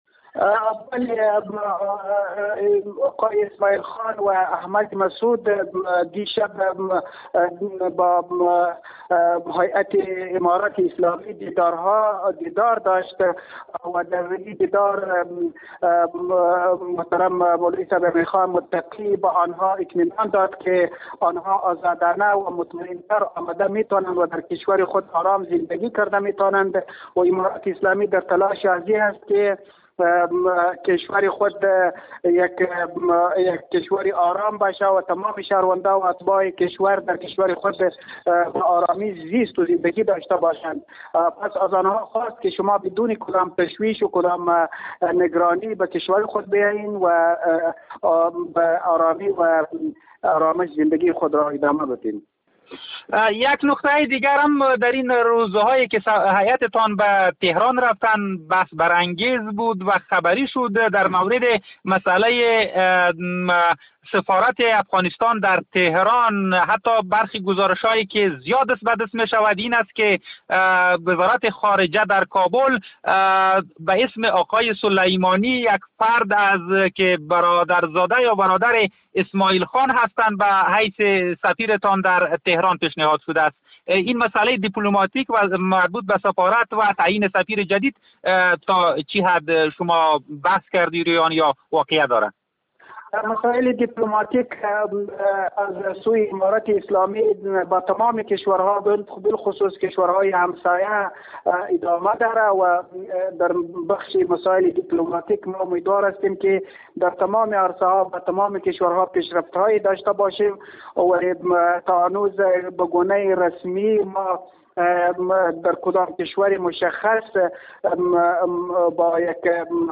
گفتگوی خبرنگار رادیودری با مولوی بلال کریمی، معاون سخنگوی حکومت طالبان: